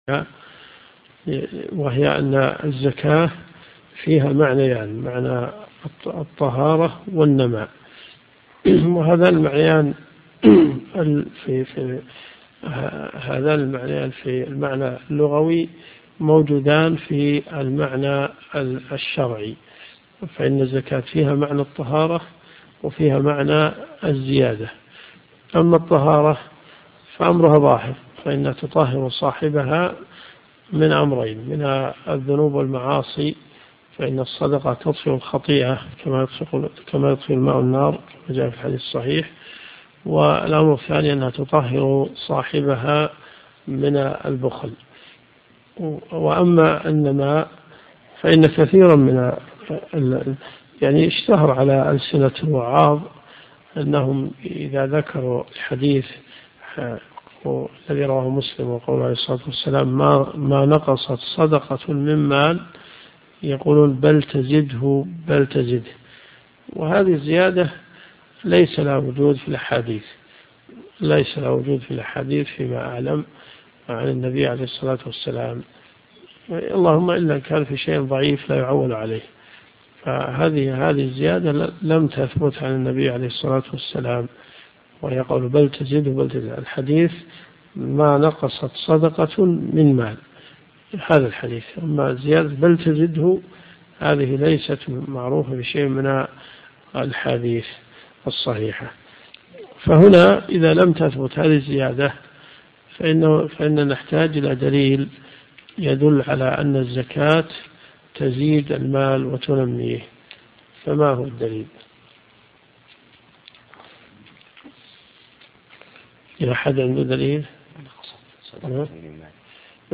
صحيح البخاري . كتاب الزكاة - من حديث 1409 -إلى- حديث - 1419 - الدرس في الدقيقة 8.40.